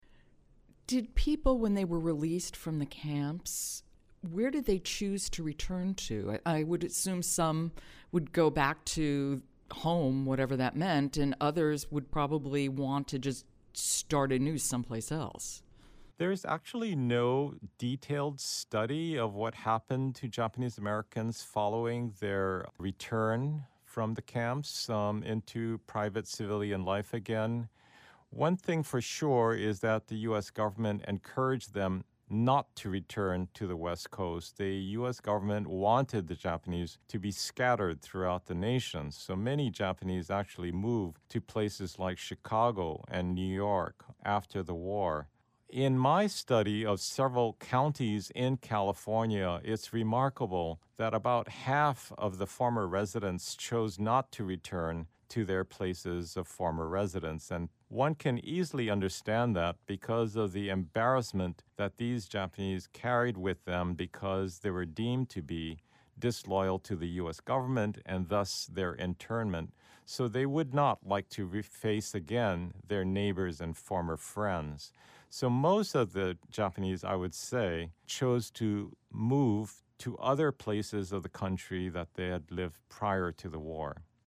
Author and scholar